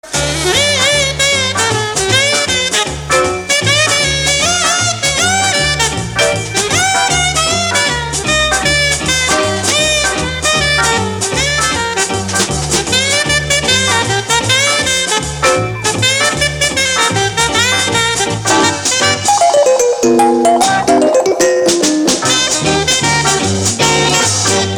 • Качество: 320, Stereo
веселые